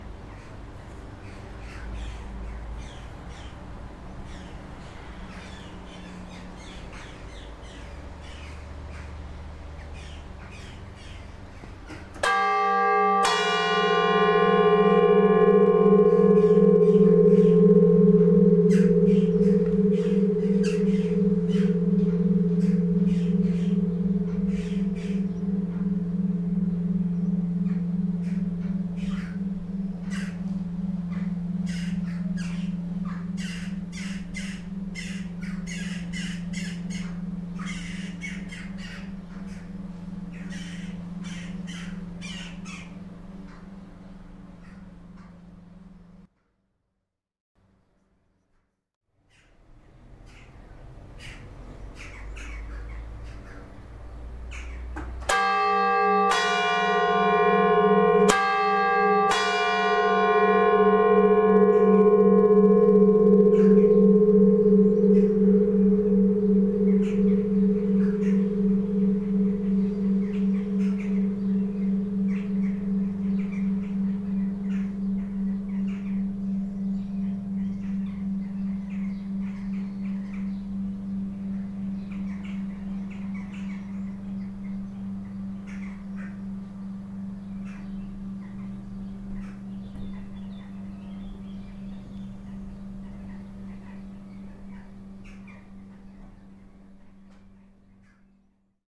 Il y a trois cloches. Elles sont toutes trois tintées avec des marteaux tous neufs, électromagnétiques.
Ce sont des cloches de lancer franc, il n'y a pas de mouton.
Elles ont été coulées en 1808 par le fondeur lorrain Thouvenot.
Vous pouvez écouter le tintement des cloches ici :